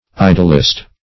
Idolist \I"dol*ist\